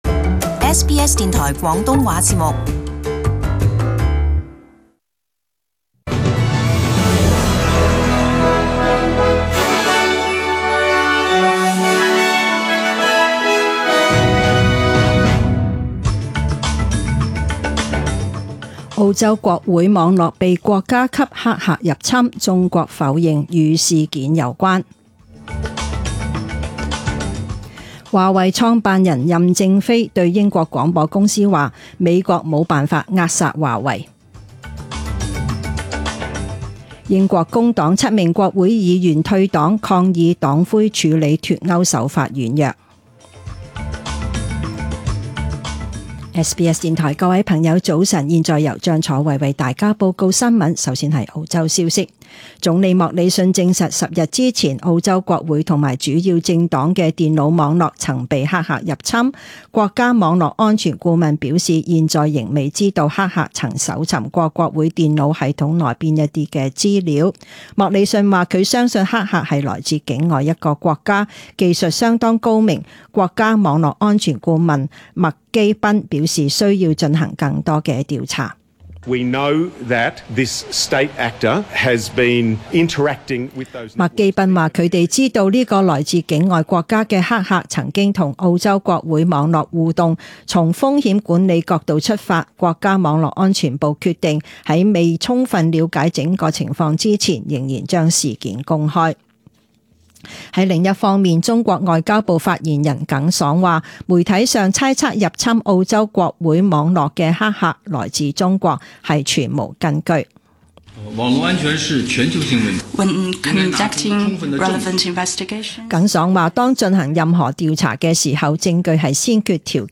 Chinese (Cantonese) News Source: SBS News